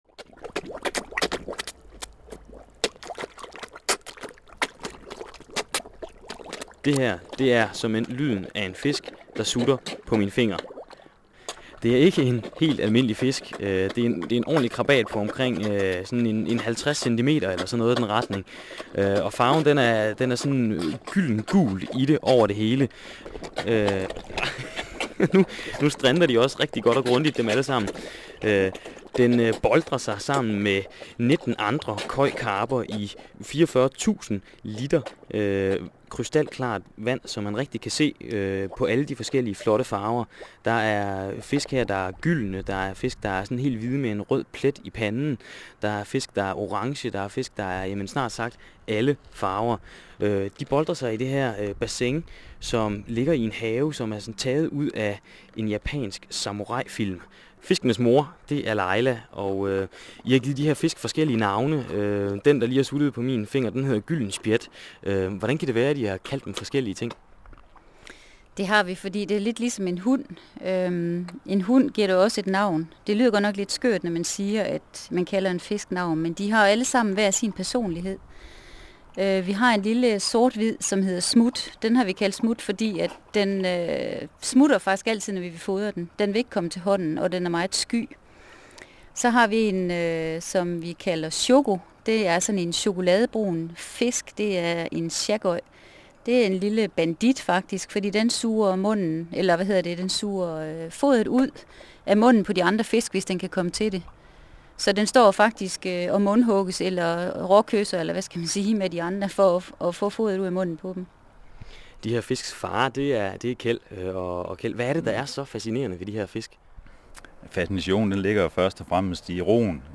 Radioindslag